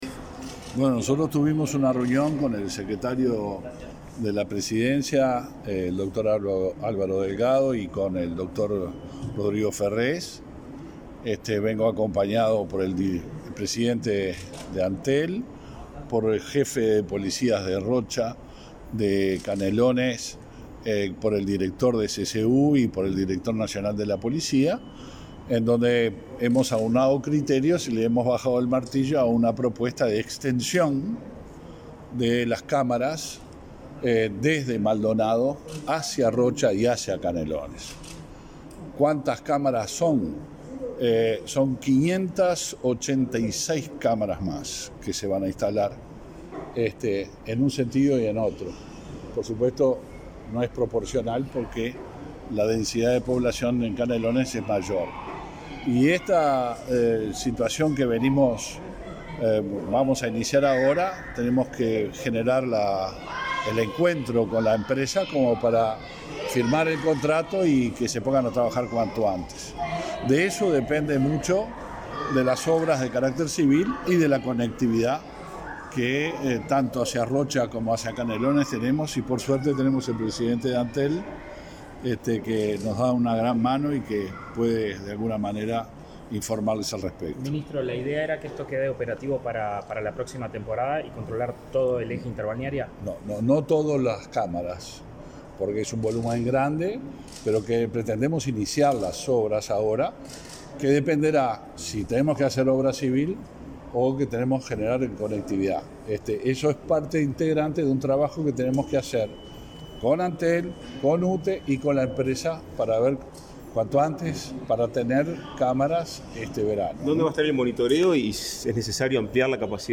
Declaraciones del ministro Luis Alberto Heber y el presidente de Antel, Gabriel Gurméndez
Luego dialogaron con la prensa sobre la instalación de cámaras de videovigilancia.